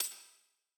ENE Perc Hit.wav